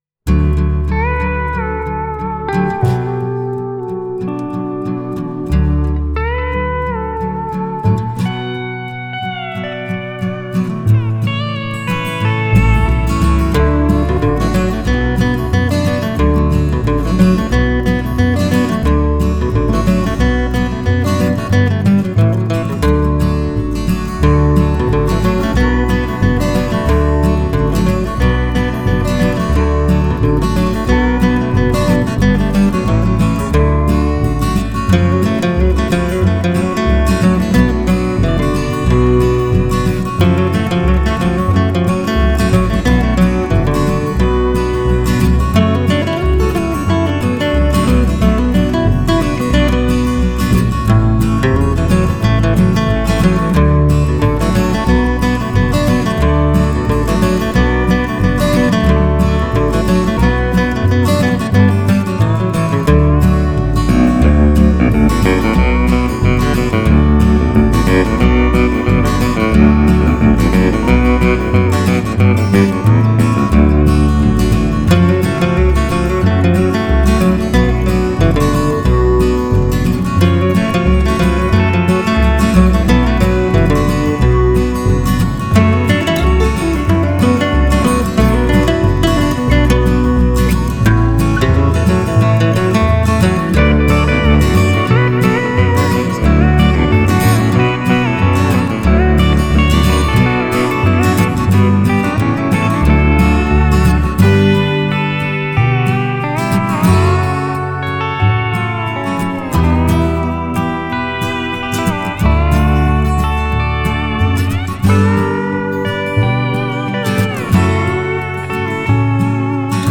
vocals, acoustic guitar
MSA pedal steel